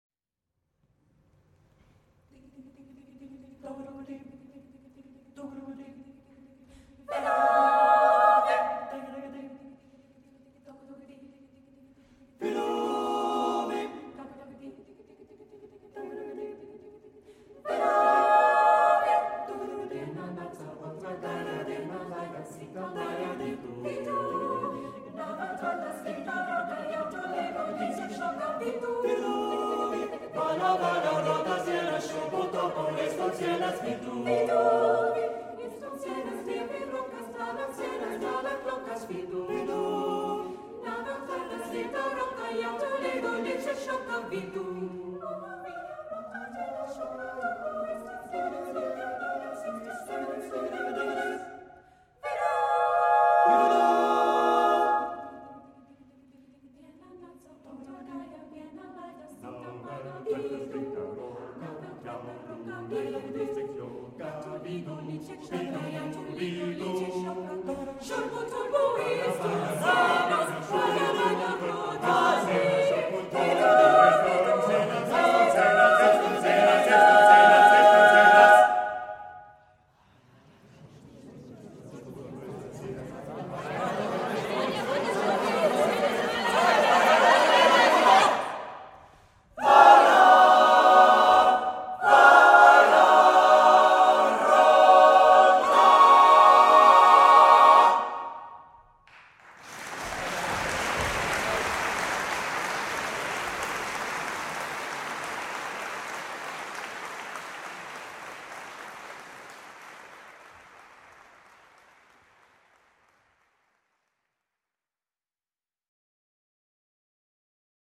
choral singing